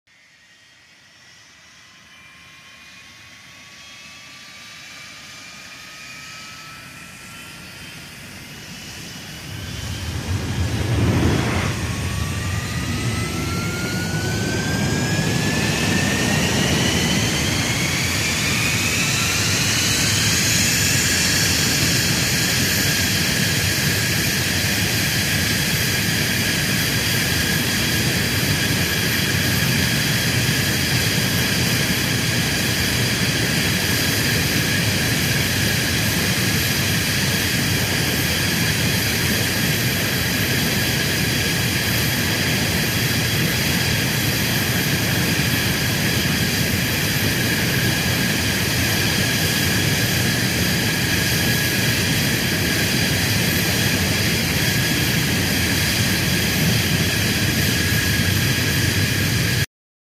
Звуки турбины самолета
Хороший звук турбины самолета набирающей обороты